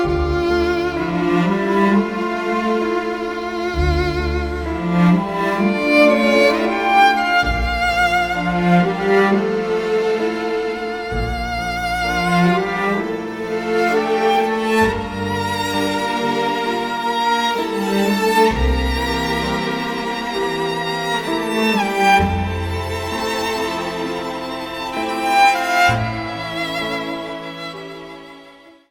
Category: Violin Ringtones